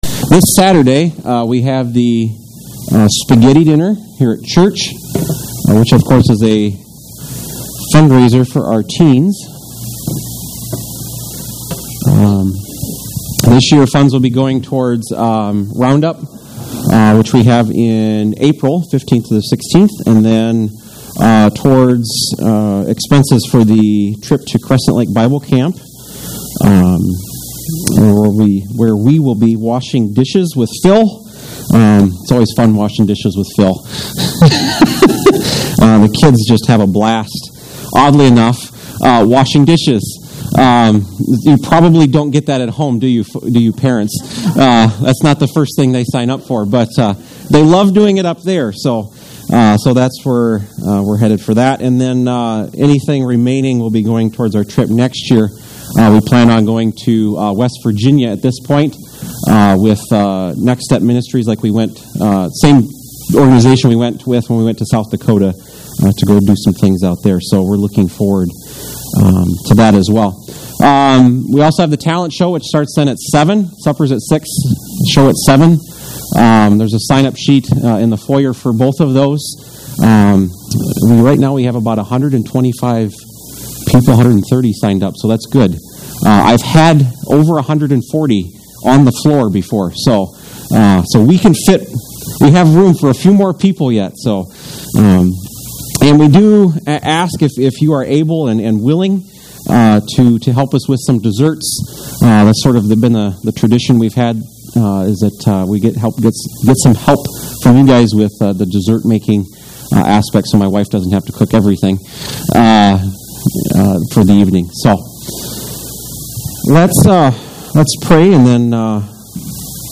Take some time to listen to this sermon on Psalm 23 and